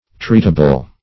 Treatable \Treat"a*ble\, a. [OE. tretable, F. traitable, L.